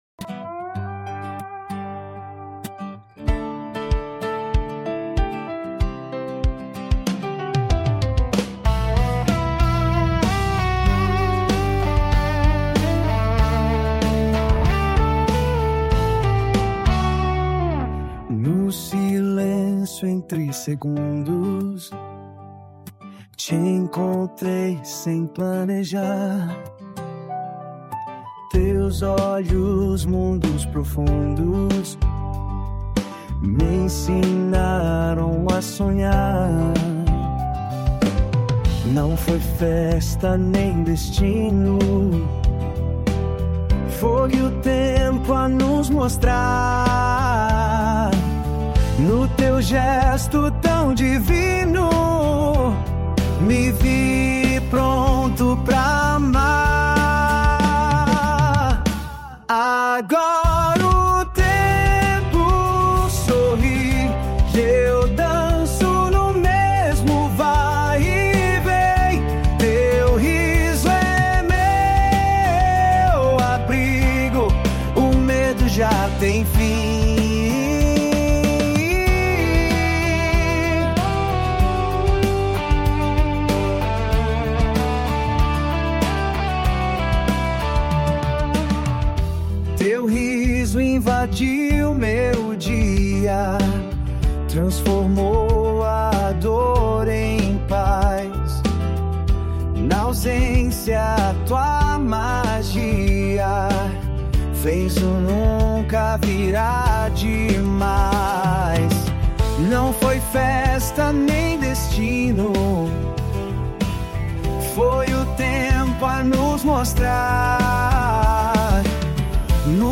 EstiloWorld Music